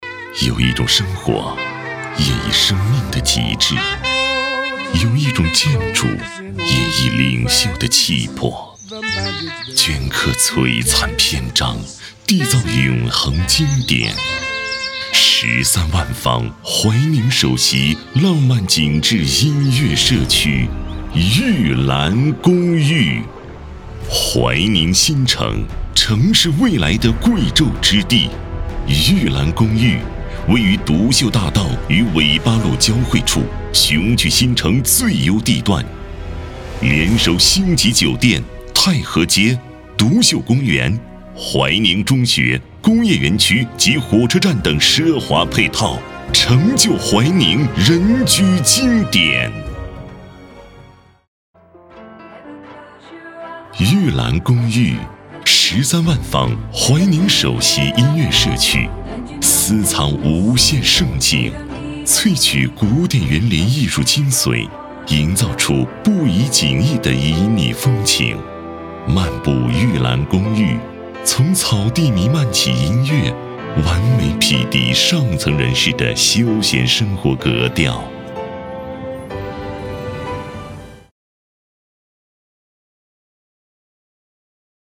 配音风格： 大气 讲述 活力 浑厚